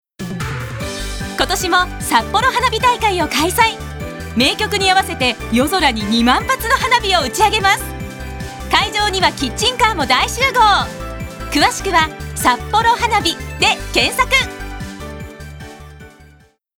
Voice／メゾソプラノ
ボイスサンプル